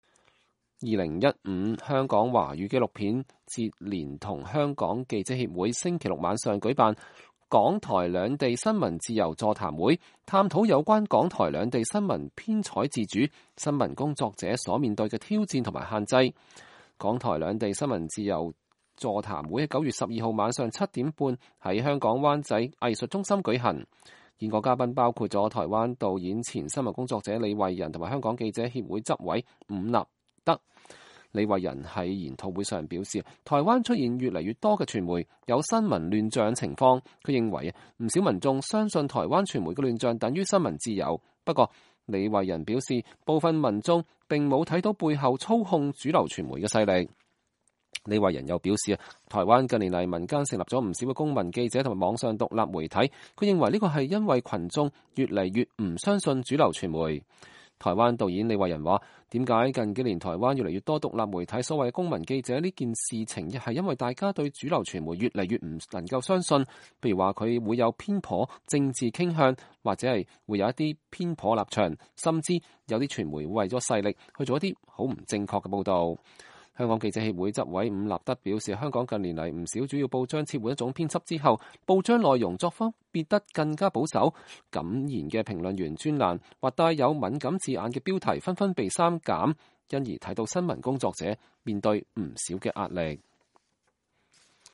2015-09-13 美國之音視頻新聞:港台兩地新聞自由座談會分析傳媒現況
2015香港華語紀錄片節連同香港記者協會星期六晚上舉辦“港台兩地新聞自由”座談會，探討有關港台兩地新聞編採自主、新聞工作者所面對的挑戰和限制。